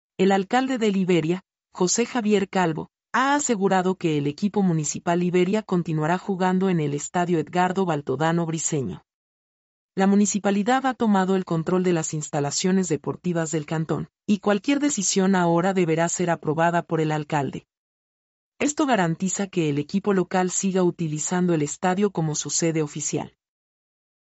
mp3-output-ttsfreedotcom-62-1.mp3